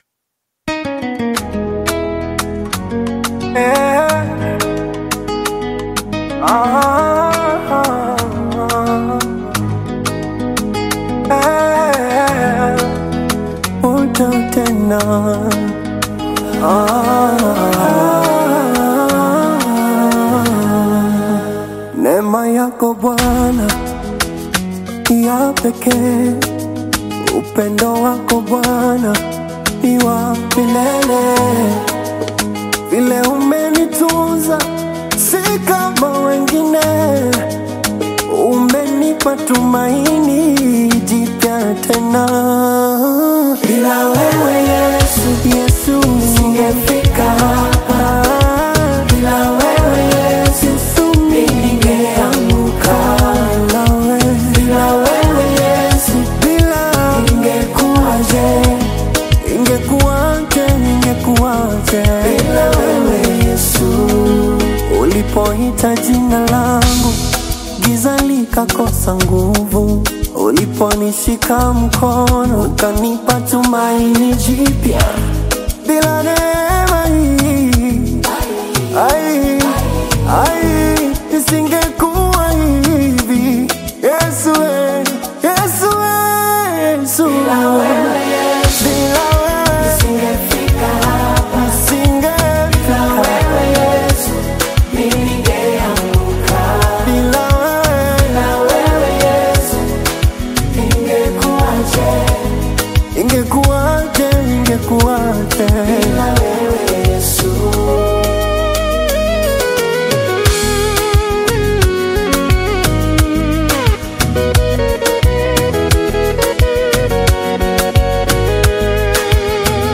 Tanzanian gospel single
worship singer
Swahili worship lyrics
soulful vocals and heartfelt gospel expression